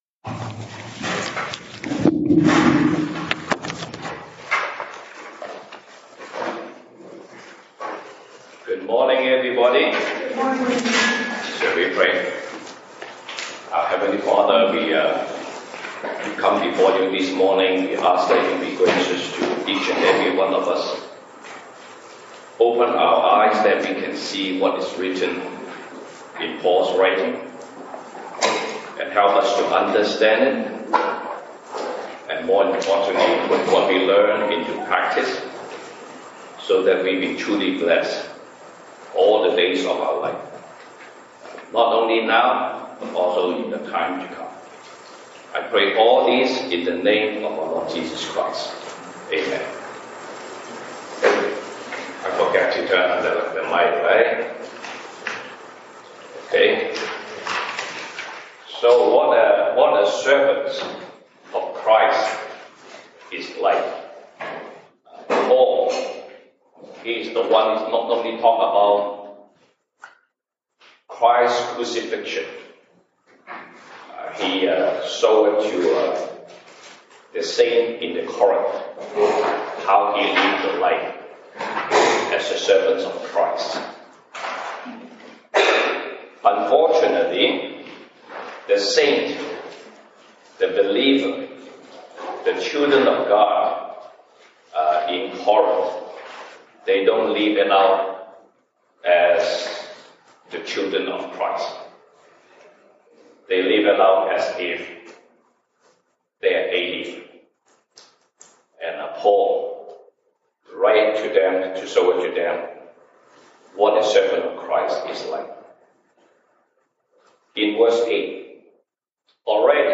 1 Corinthians Passage: 歌林多前書 1 Corinthians 4:1-21 Service Type: 西堂證道 (英語) Sunday Service English Topics